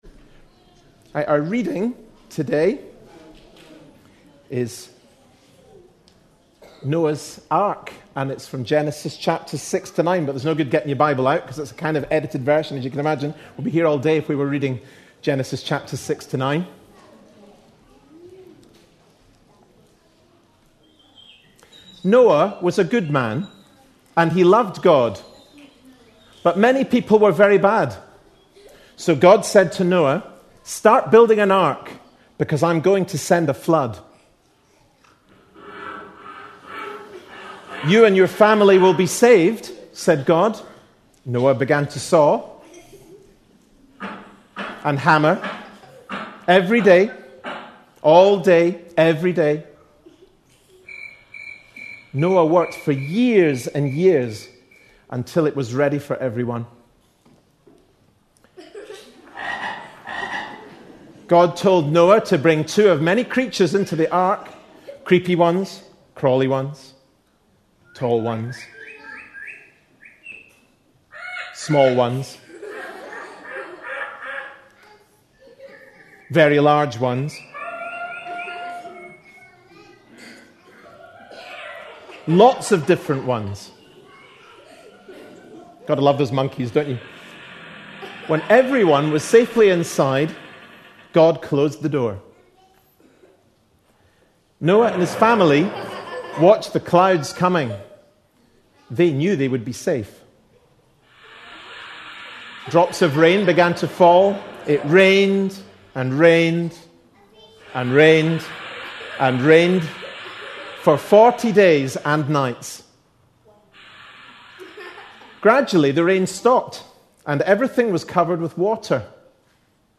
A sermon preached on 20th June, 2010.
Genesis 9:11-17 Listen online Details This was an all-age service, featuring a summary of Noah's story (with sound effects) from Genesis 6-9, and audience participation.